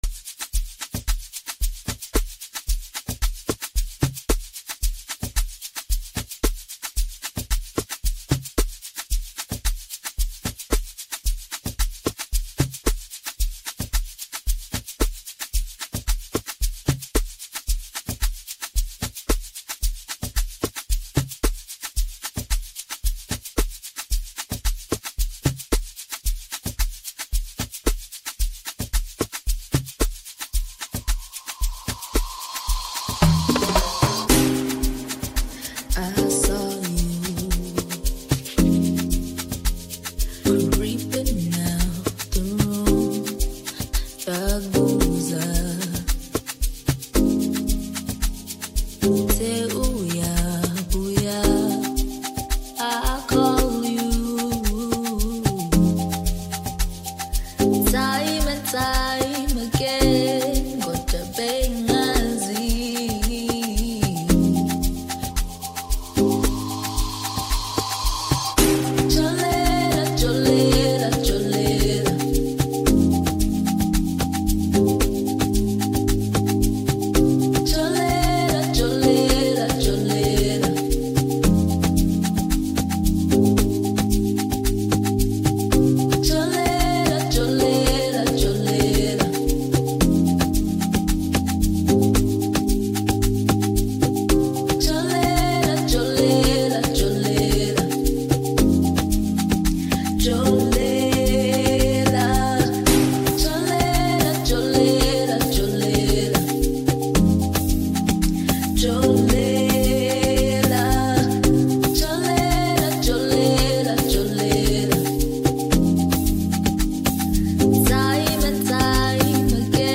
soothing vocals